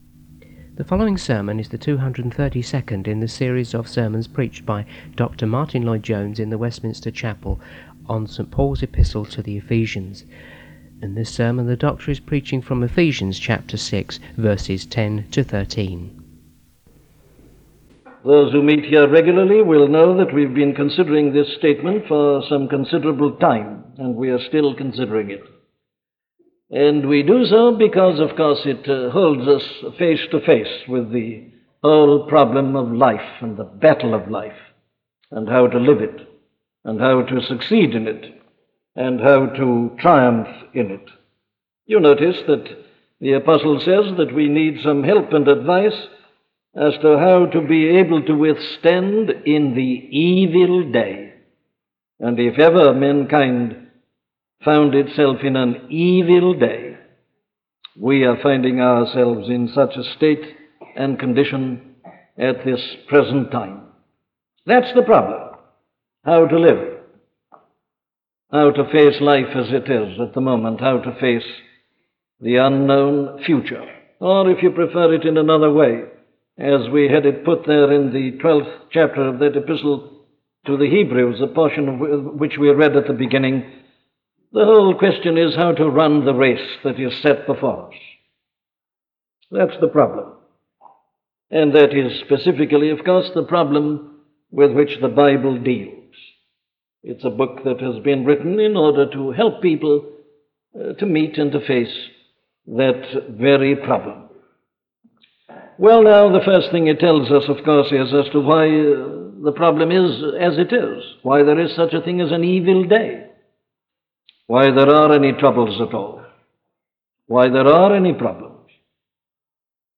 Exercise - a sermon from Dr. Martyn Lloyd Jones
Listen to the sermon on Ephesians 6:10-13 'Exercise' by Dr. Martyn Lloyd-Jones